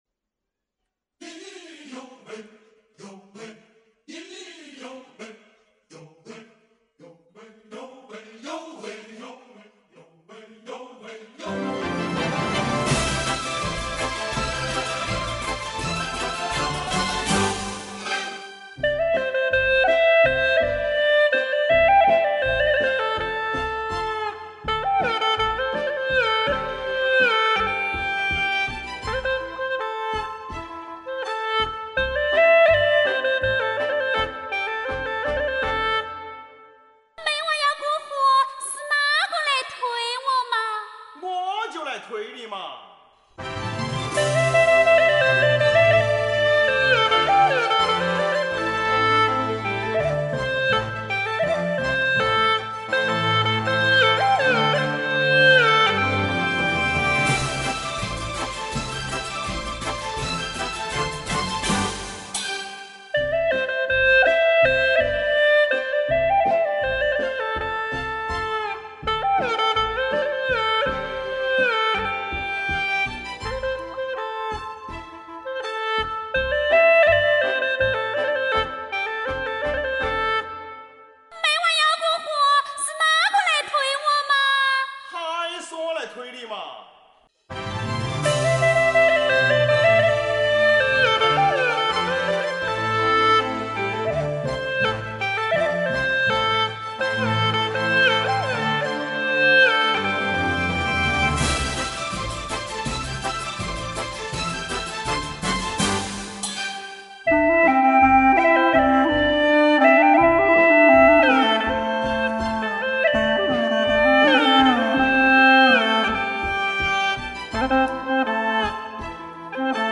调式 : C 曲类 : 民族 此曲暂无教学 点击下载 1958年，《龙船调》列入湖北群众艺术巡回辅导演出团演出节目，《龙船调》从此一发而不可收，传唱到全省、全国乃至全世界。
【大小C调】
优美的旋律,独特的演奏风格,太醉人啦！